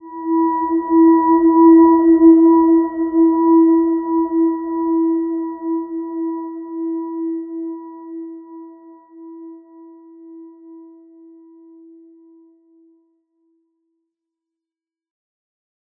Silver-Gem-E4-mf.wav